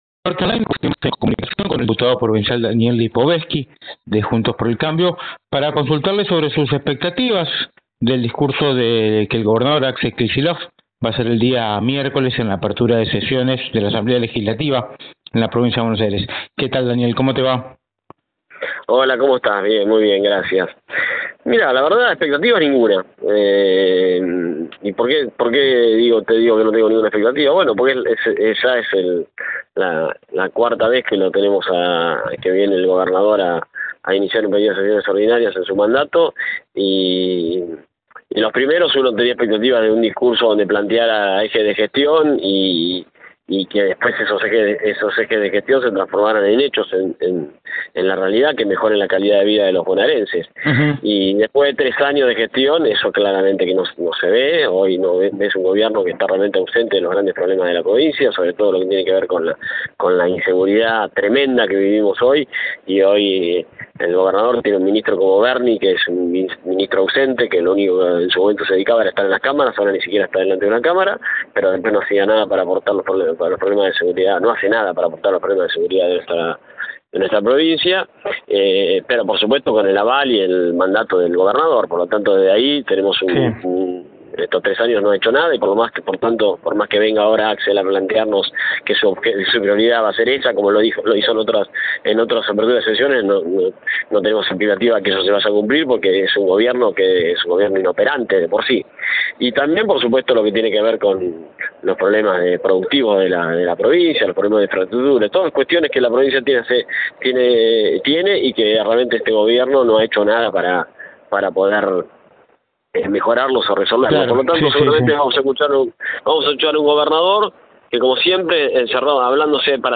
El diputado provincial de Juntos por el Cambio, Daniel Lipovetzky, habló en exclusiva con NorteOnline y se refirió a la apertura de sesiones ordinarias de este miércoles en La Plata.